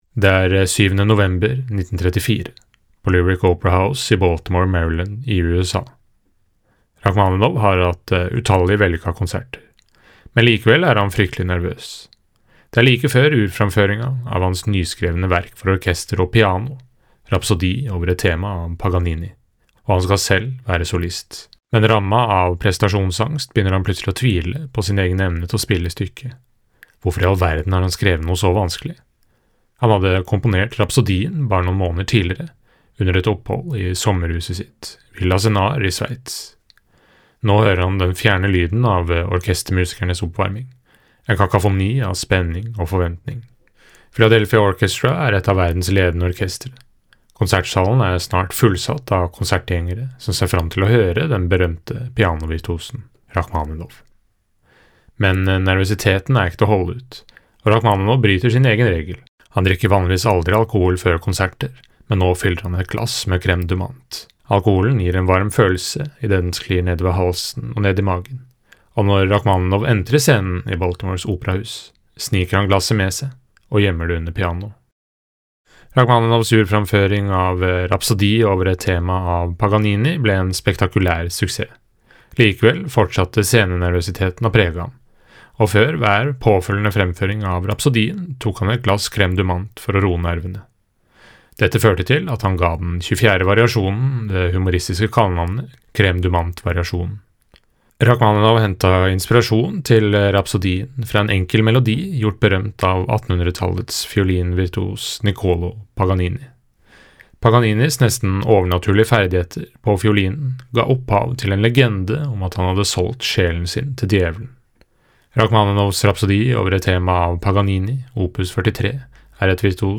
VERKOMTALE-Sergej-Rachmaninoffs-Rapsodi-over-et-tema-av-Paganini.mp3